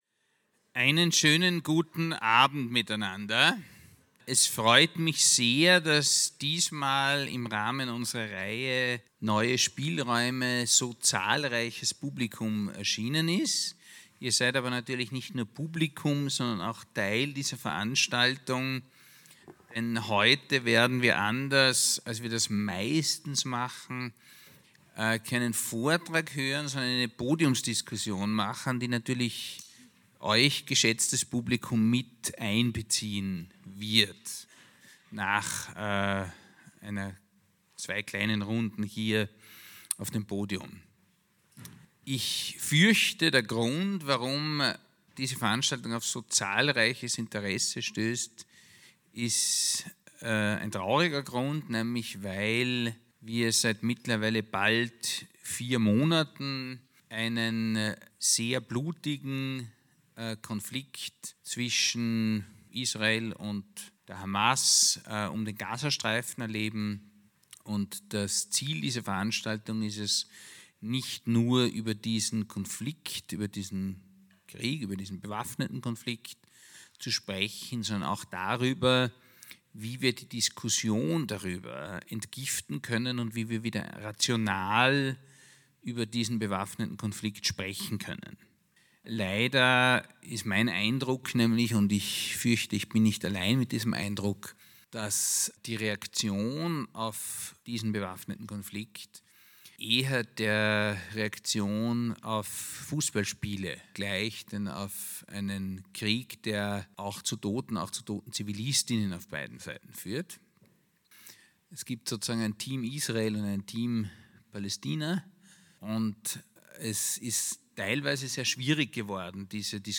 Neue Spielräume - Gaza - 1.Teil - Debatte.mp3